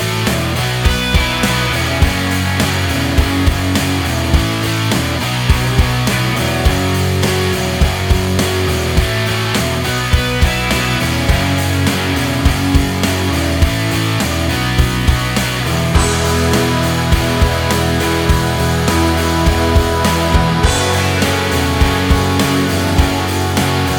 no Backing Vocals Indie / Alternative 4:28 Buy £1.50